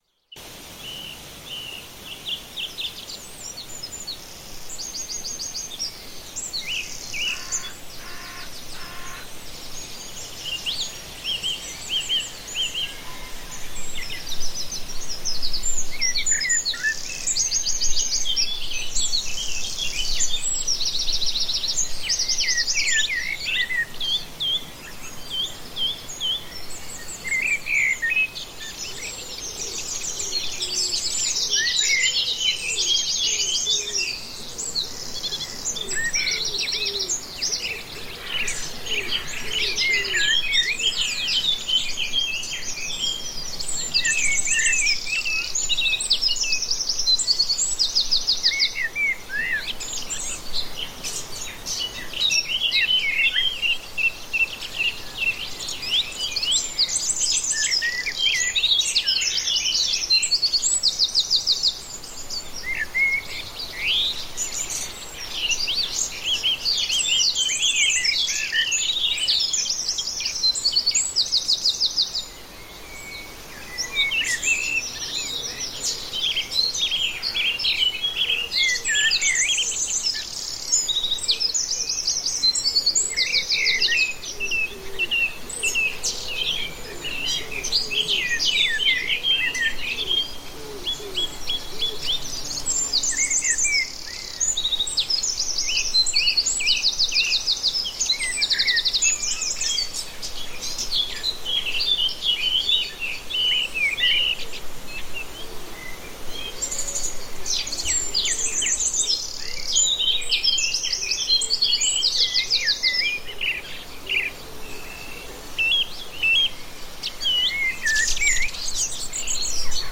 FRÜHLINGSBLÜTE: Blumenwald-Blüte mit Natur-Stimmen in voller Pracht